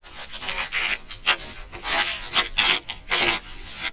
ALIEN_Communication_11_mono.wav